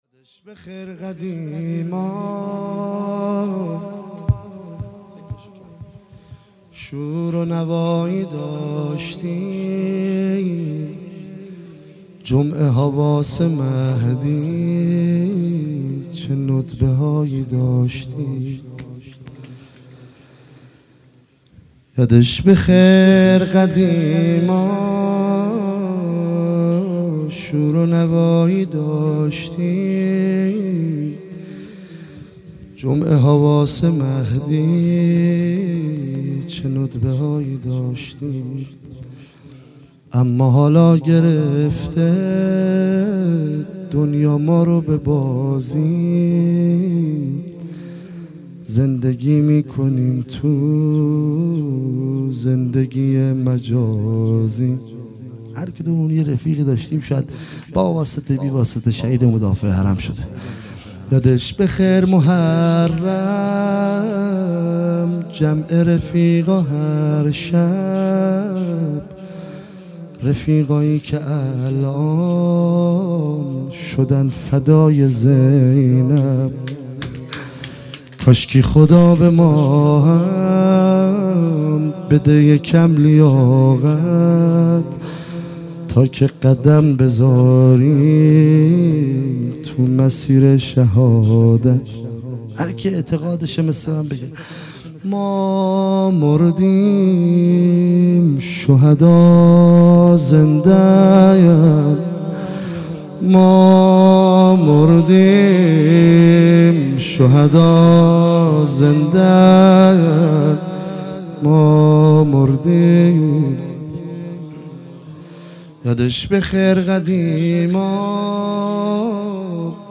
مراسم شب پنجم محرم ۱۳۹۷